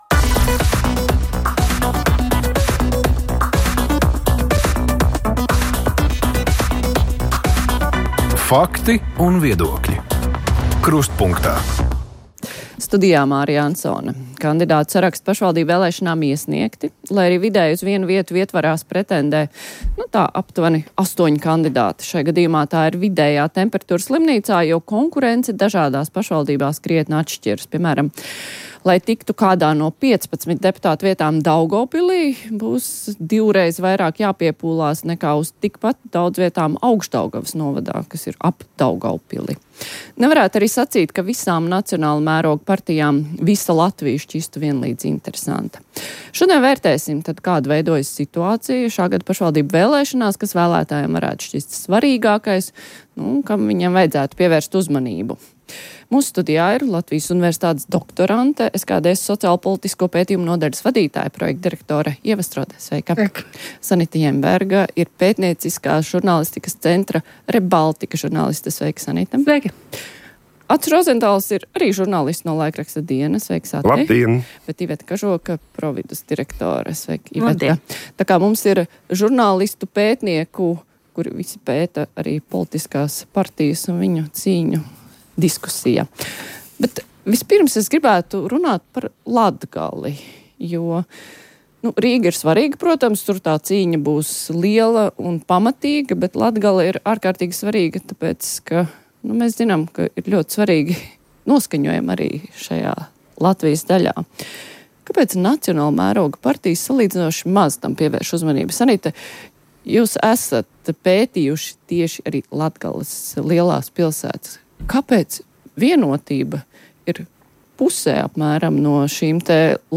Krustpunktā Lielā intervija